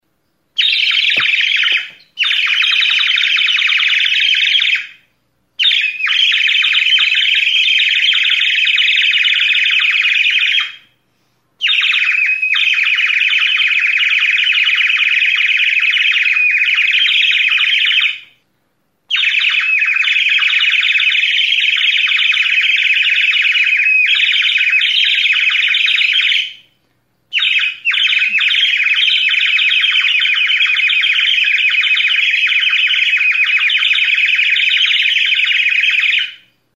Recorded with this music instrument.
RUISEÑOR; Ur flauta
Aerophones -> Flutes -> Ocarina
Okarina gisako ur flauta da.
CLAY; CERAMICS